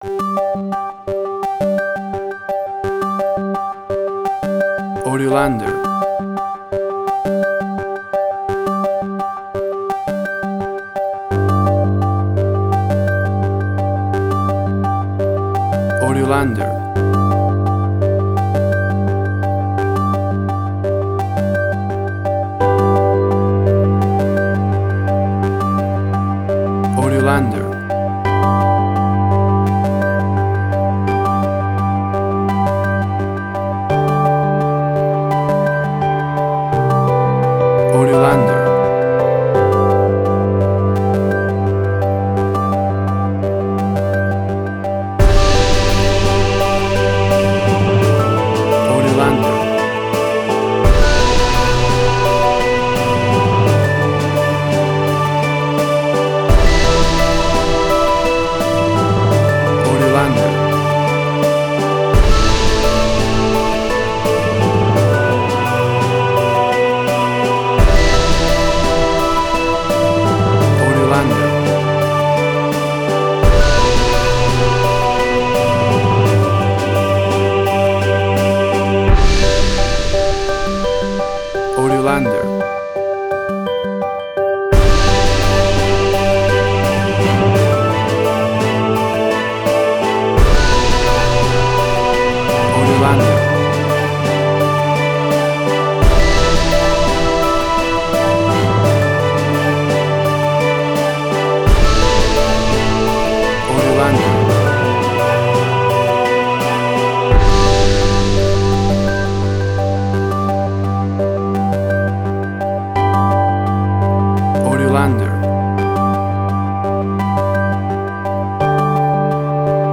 Suspense, Drama, Quirky, Emotional.
Tempo (BPM): 85